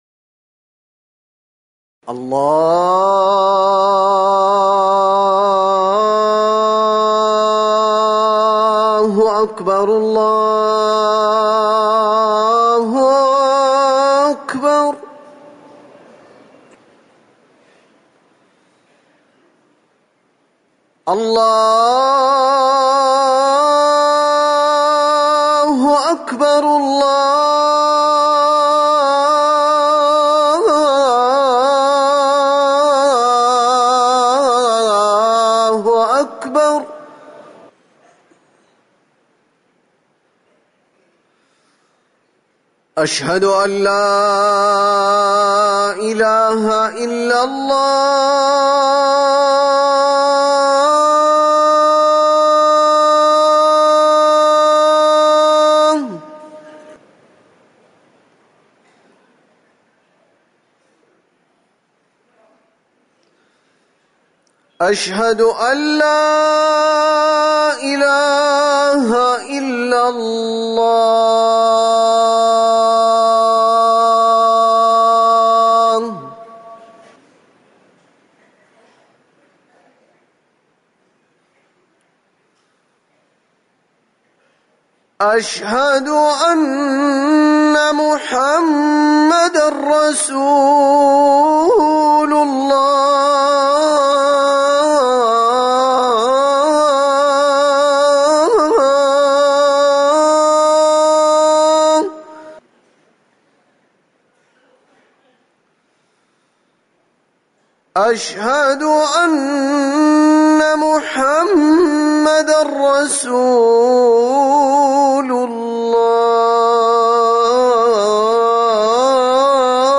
أذان الظهر
تاريخ النشر ١٣ محرم ١٤٤١ هـ المكان: المسجد النبوي الشيخ